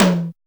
626 TOM2 HI.wav